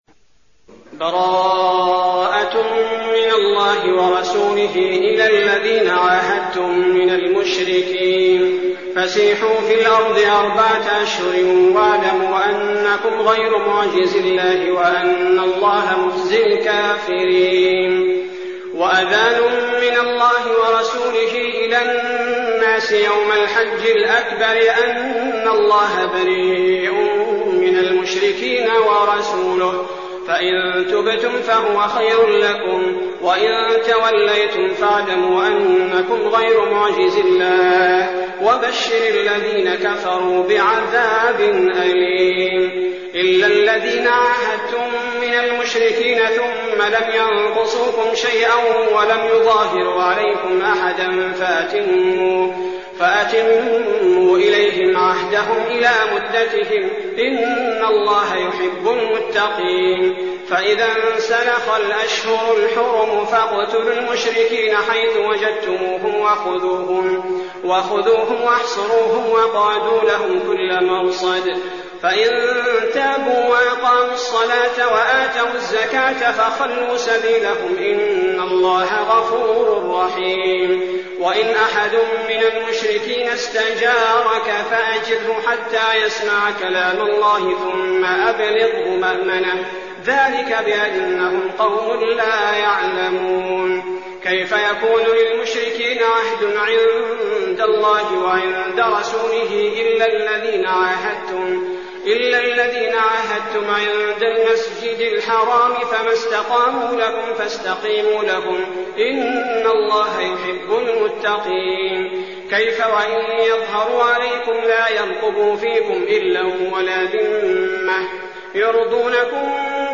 المكان: المسجد النبوي الشيخ: فضيلة الشيخ عبدالباري الثبيتي فضيلة الشيخ عبدالباري الثبيتي التوبة